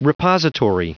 Prononciation du mot repository en anglais (fichier audio)
Prononciation du mot : repository